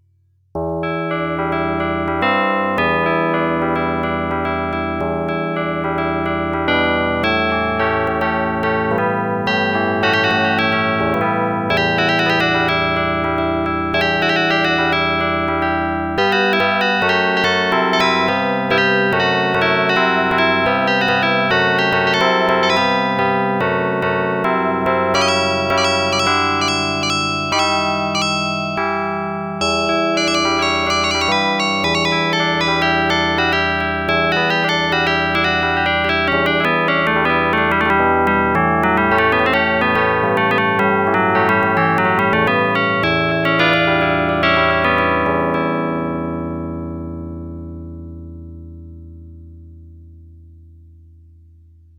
Ma iste effecto stereophonic es dur: un instrumento es integremente in le canal sinistre, le altere completemente in le canal dextre.
stereo dur,
HardStereo.ogg